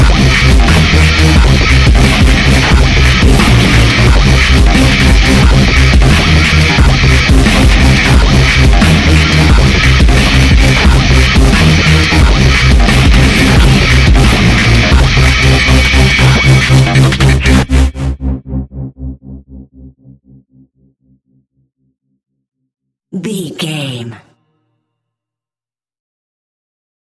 Epic / Action
Fast paced
Aeolian/Minor
aggressive
dark
intense
synthesiser
drum machine
futuristic
breakbeat
energetic
synth leads
synth bass